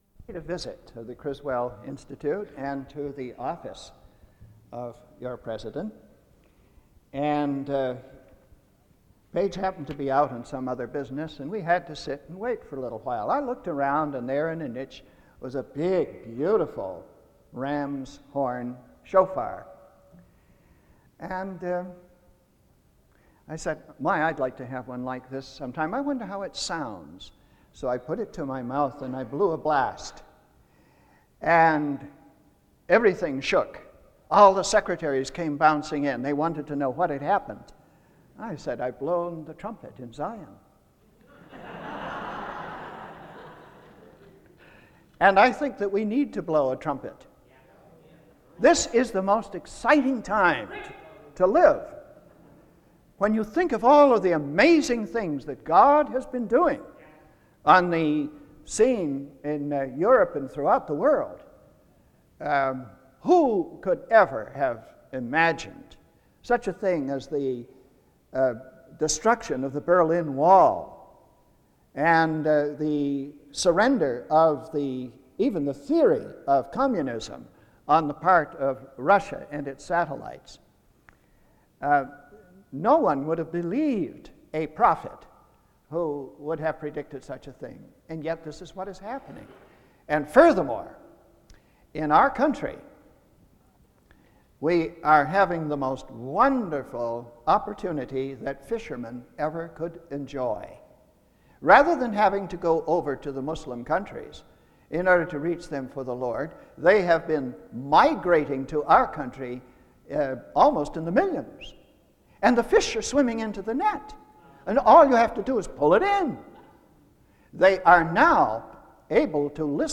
SEBTS Chapel - Gleason L. Archer, Jr. February 2, 1995
In Collection: SEBTS Chapel and Special Event Recordings SEBTS Chapel and Special Event Recordings - 1990s Miniaturansicht Titel Hochladedatum Sichtbarkeit Aktionen SEBTS_Chapel_Gleason_L_Archer_Jr_1995-02-02.wav 2026-02-12 Herunterladen